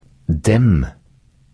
Diktat en eller to m-er_klem.mp3